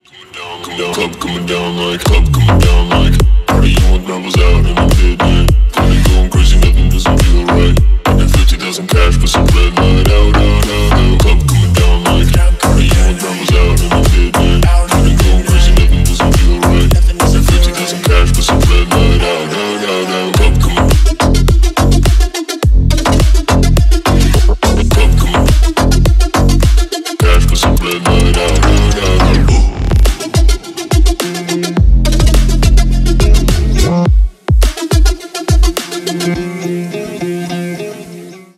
edm
басы , громкие